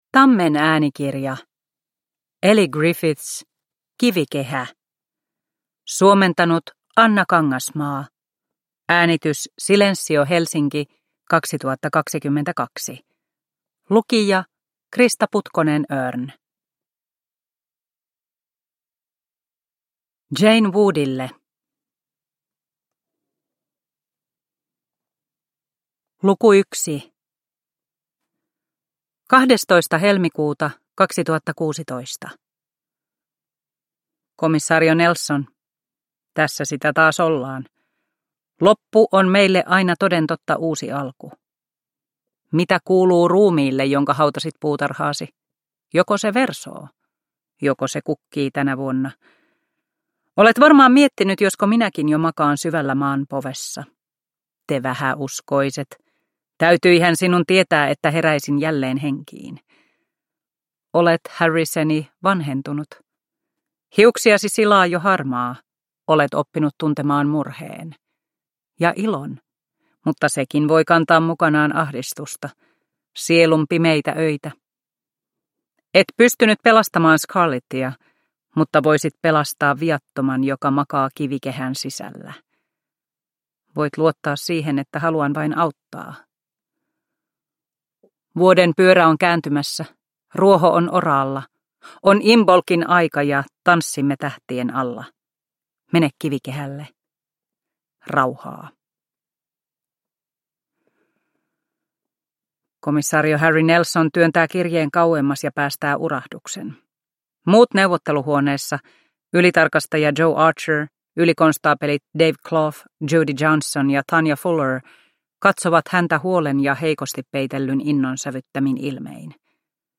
Kivikehä – Ljudbok – Laddas ner